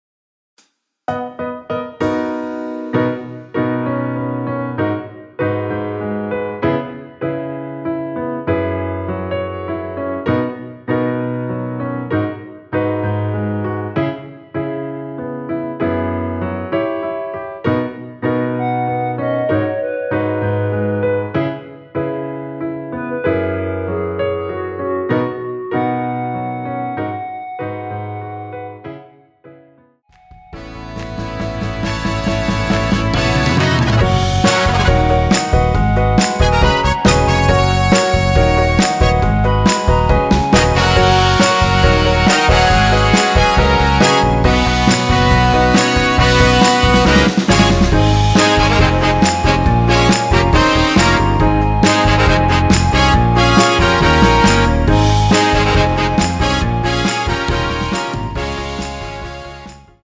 전주 없이 시작 하는 곡이라 인트로 만들어 놓았습니다.
원키에서(+4)올린 멜로디 포함된 MR입니다.
노래방에서 노래를 부르실때 노래 부분에 가이드 멜로디가 따라 나와서
앞부분30초, 뒷부분30초씩 편집해서 올려 드리고 있습니다.
중간에 음이 끈어지고 다시 나오는 이유는